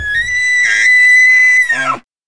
Звук крика марала